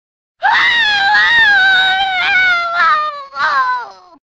Play Deception Ashrah Scream - SoundBoardGuy
deception-ashrah-scream.mp3